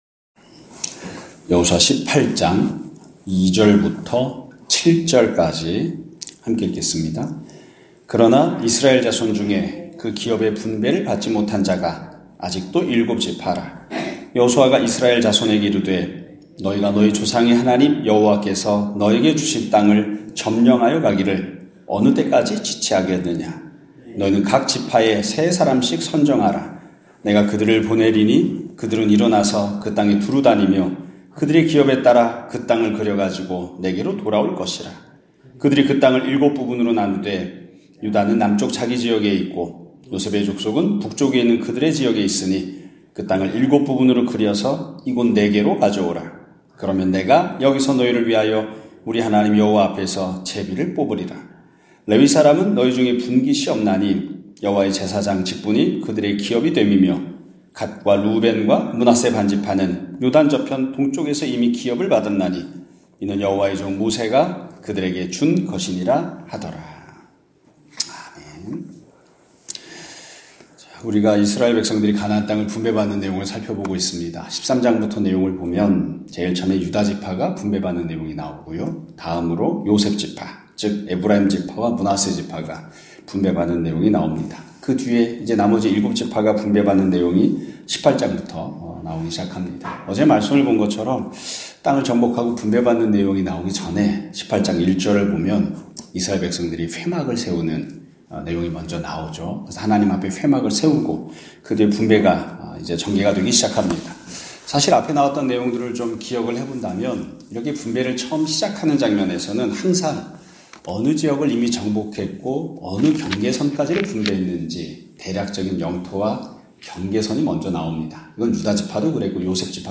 2024년 12월 24일(화요일) <아침예배> 설교입니다.